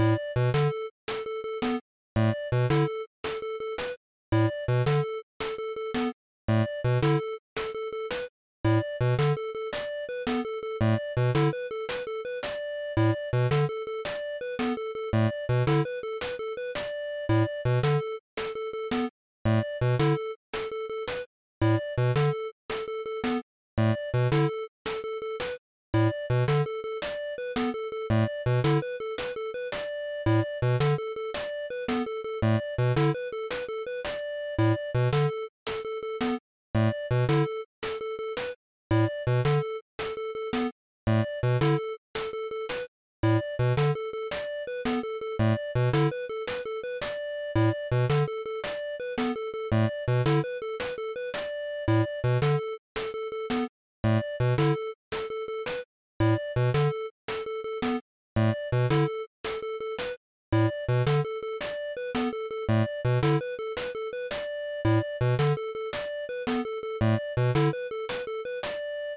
beep.mp3